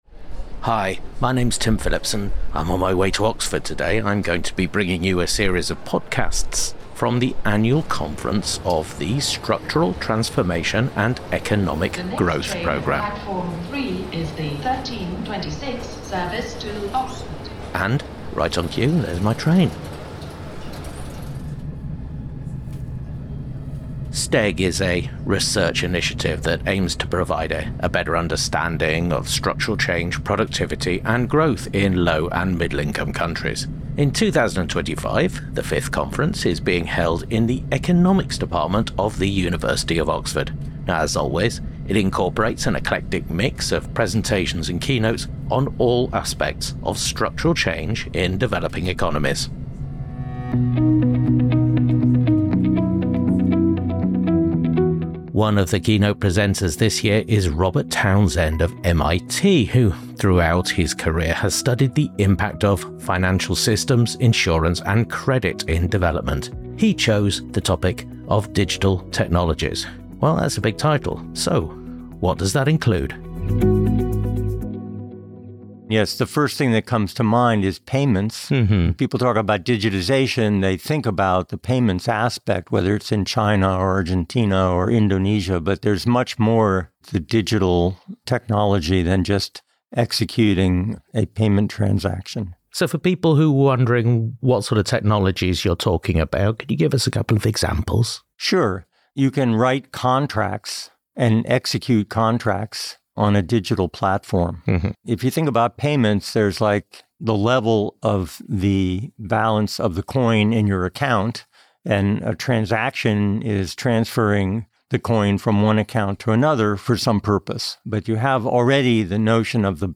Recorded at the 2025 annual conference of the Structural Transformation and Economic Growth Programme, held at the University of Oxford.